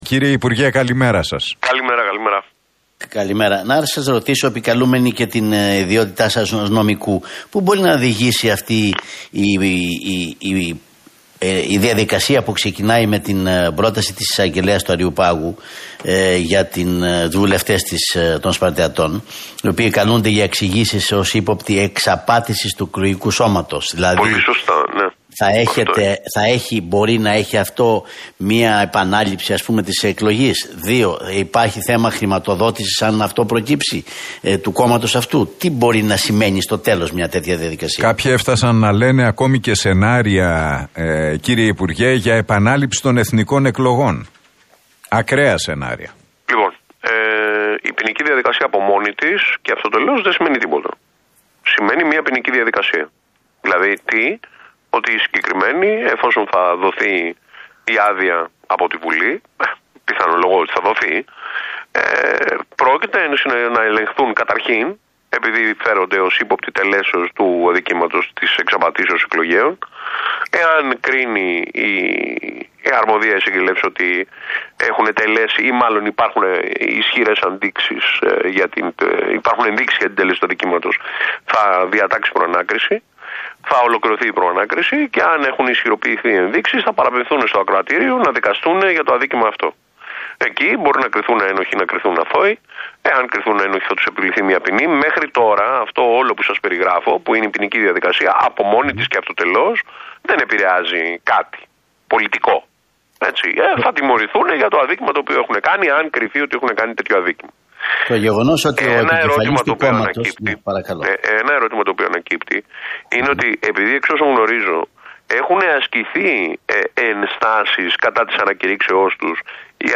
Βορίδης στον Realfm 97,8: Η ποινική διαδικασία για τους Σπαρτιάτες από μόνη της δεν επηρεάζει κάτι πολιτικό